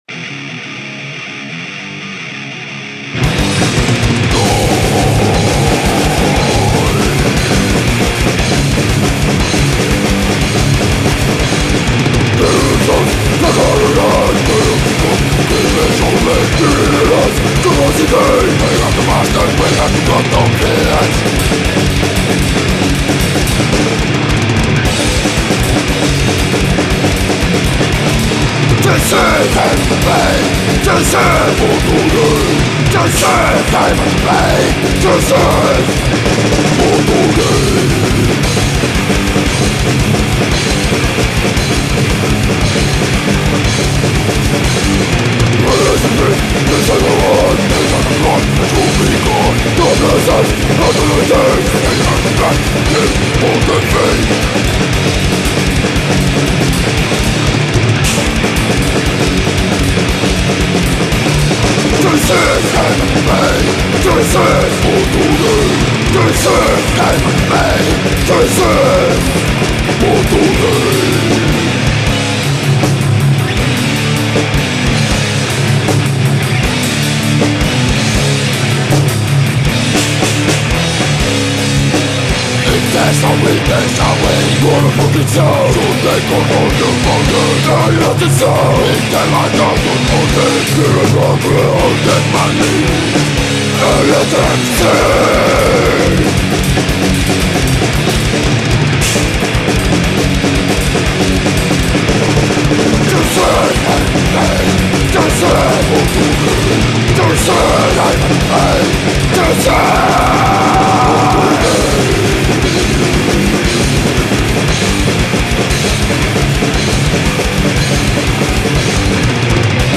ULEÅBORG HARDCORE
10 piisiä äänitetty Helgate studiolla 11.9.2005.